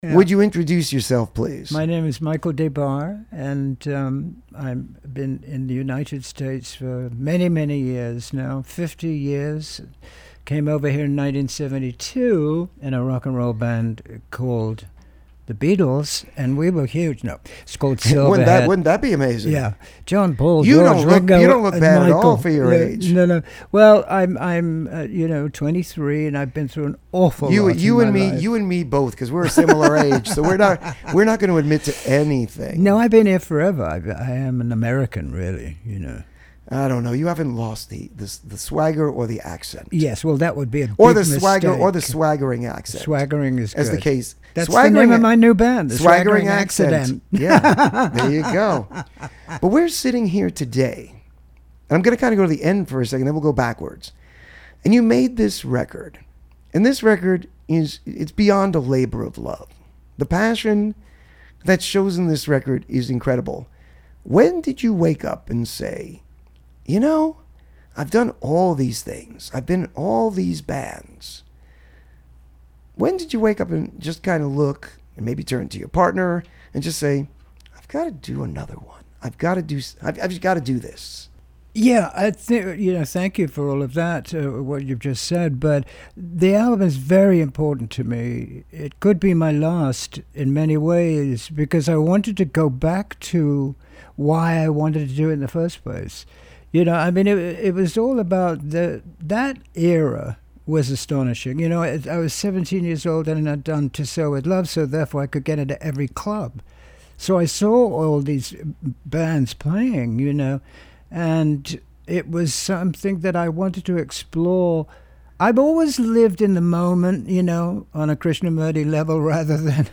This Week's Interview (10/20/2024): Michael Des Barres